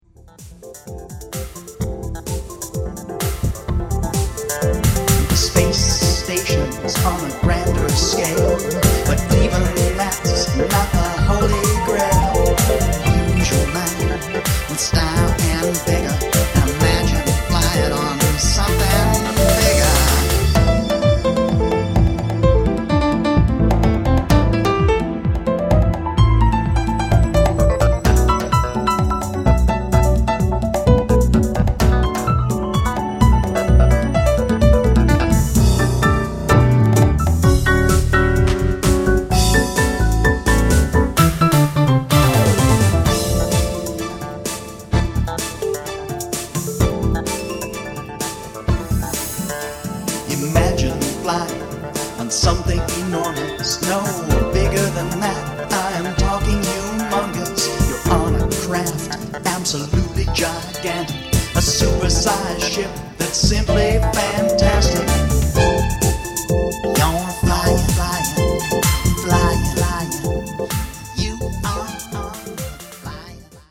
Combining house, jungle, and spoken word